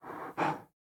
assets / minecraft / sounds / mob / panda / pant1.ogg
pant1.ogg